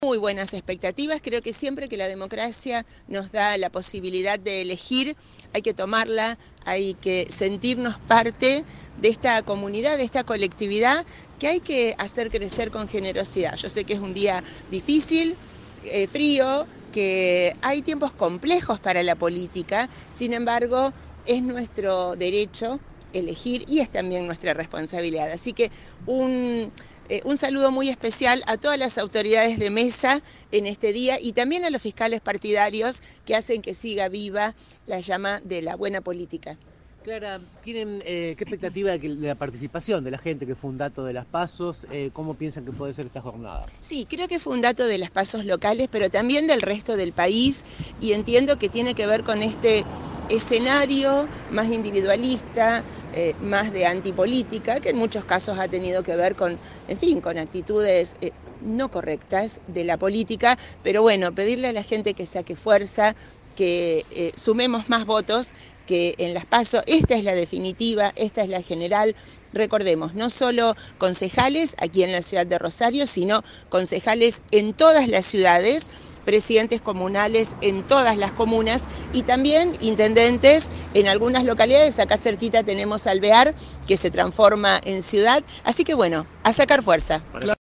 En declaraciones a la prensa tras sufragar, García resaltó las buenas expectativas que tiene sobre la jornada electoral, a pesar de las dificultades que atraviesa el país y la política en general.